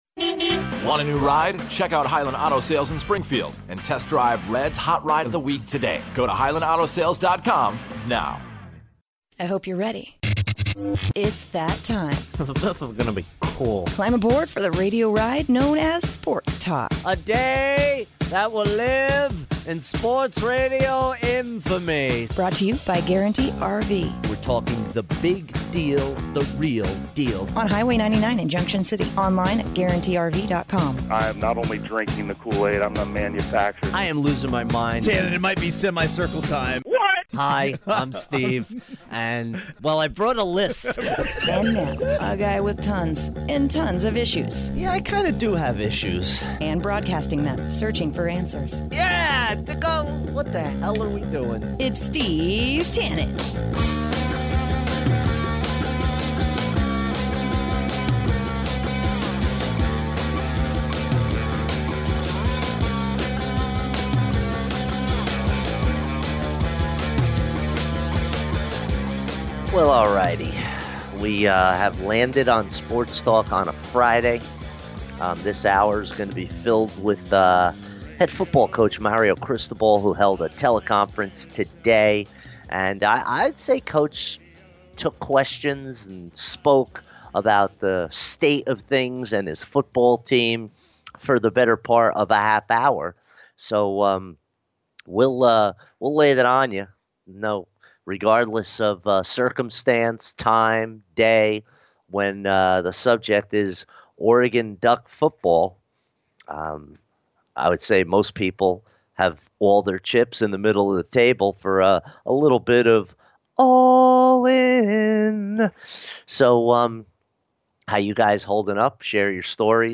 Sports Talk Hour 2: Hear from Oregon Football Head Coach Mario Cristobal during his tele-press-conference for the first time in 3 weeks, the passing of Bill Withers, and more.